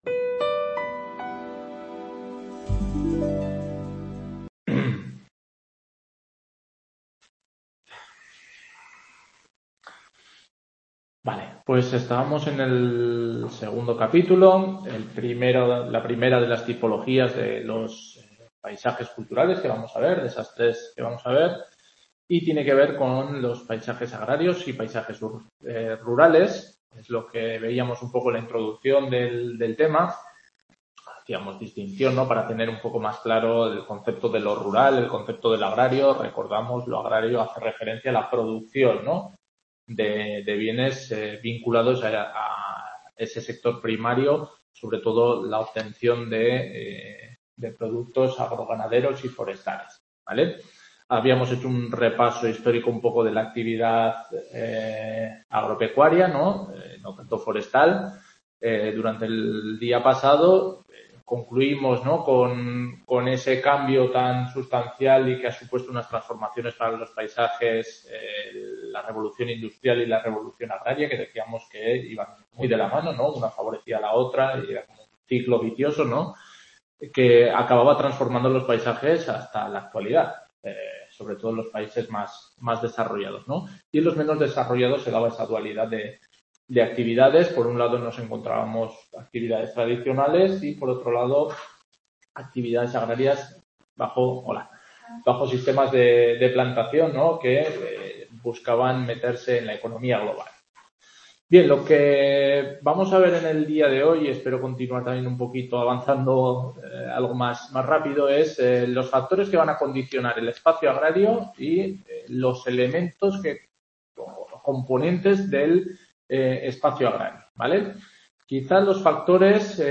Tutoría 5 - Geografía de los Paisajes Culturales